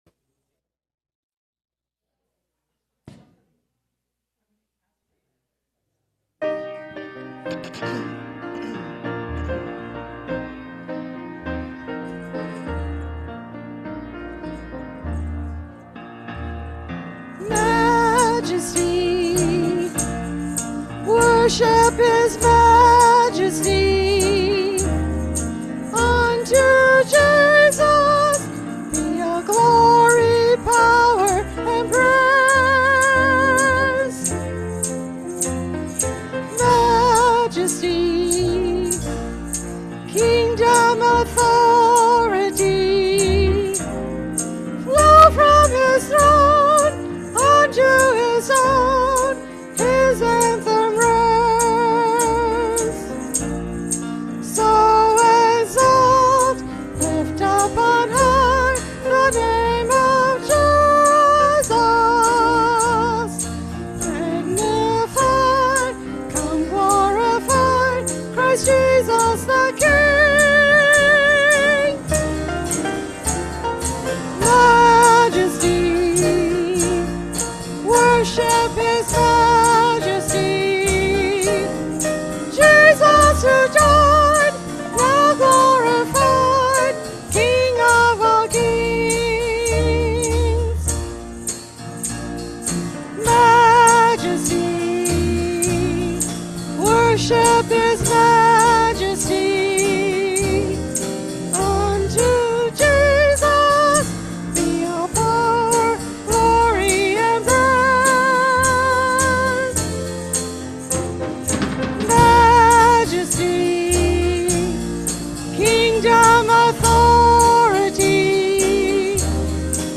Worship-February-8-2026-Voice-Only.mp3